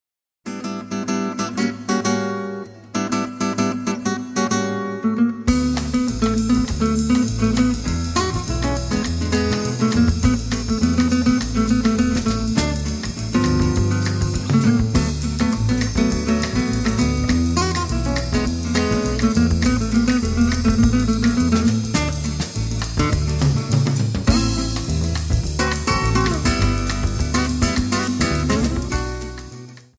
guitar
bass
drums and percussions